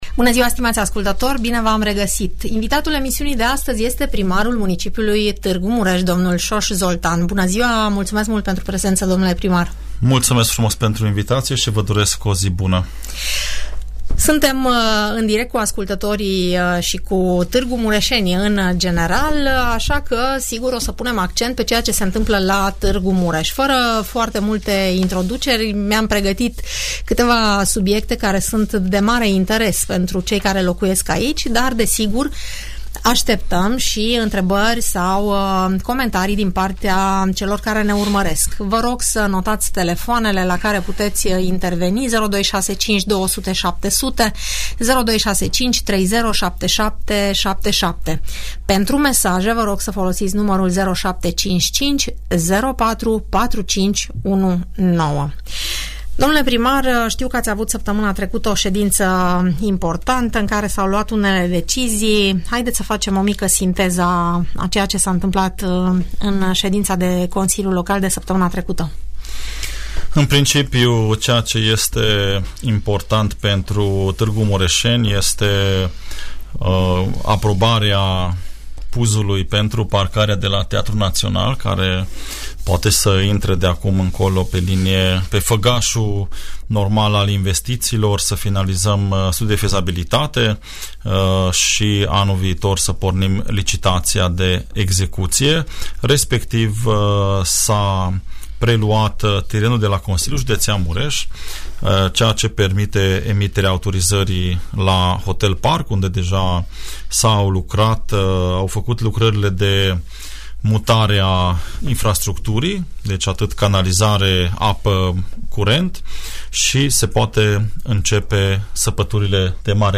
Primarul mun Tg Mureș, dl Soós Zoltán, la Radio Tg. Mureș - Radio Romania Targu Mures
ce răspunsuri are edilul municipiului, dl Soós Zoltán la întrebările ascultătorilor